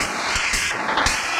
Index of /musicradar/rhythmic-inspiration-samples/170bpm